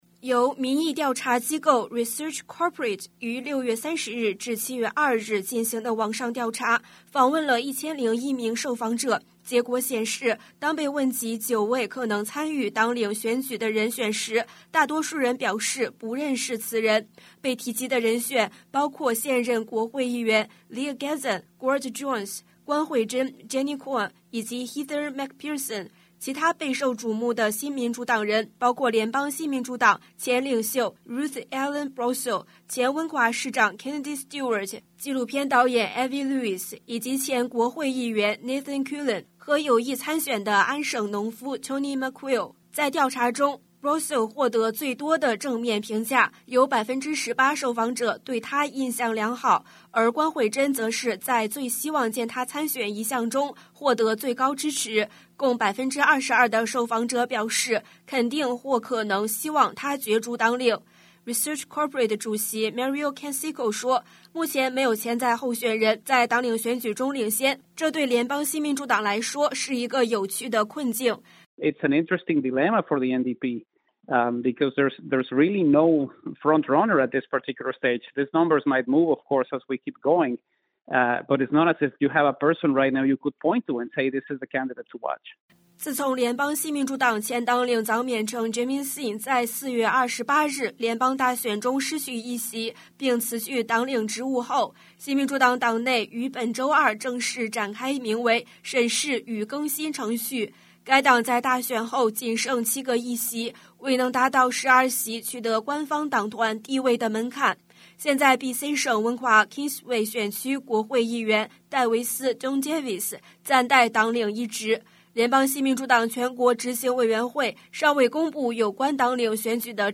news_clip_23885_mand.mp3